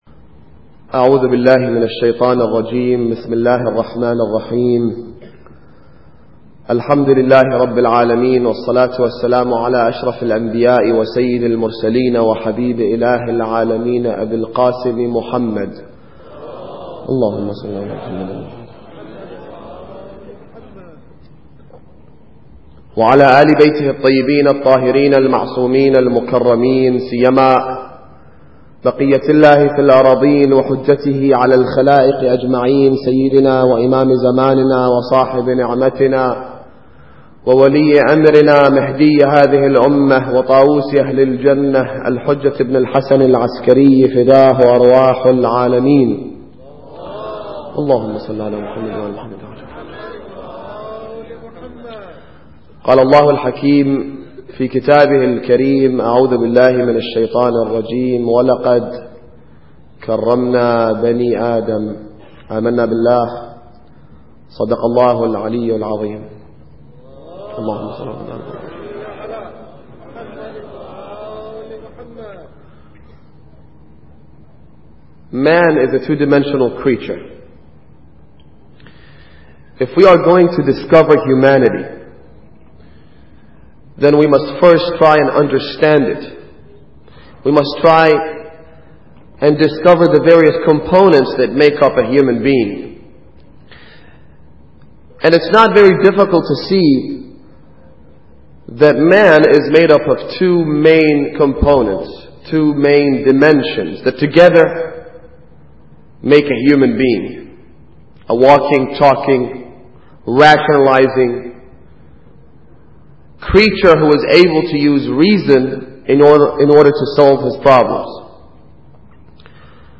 Muharram Lecture 3